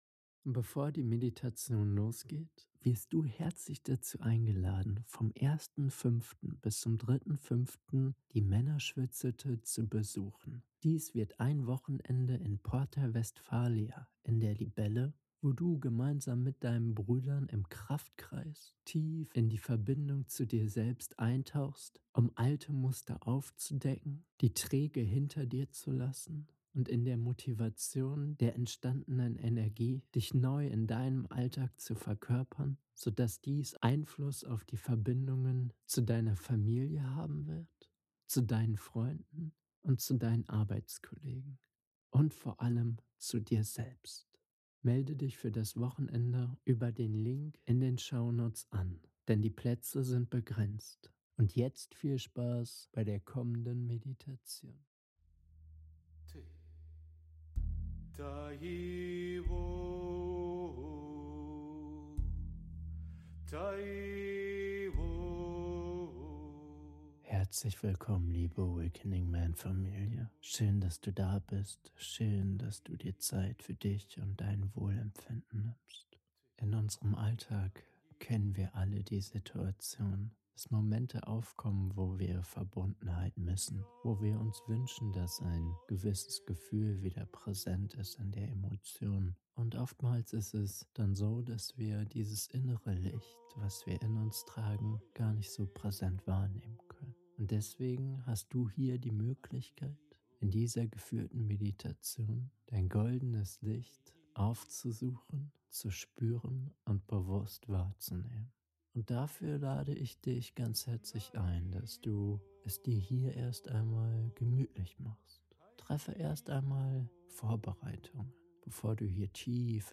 Geführte Meditation: Goldenes Licht ~ AWAKENING MEN Podcast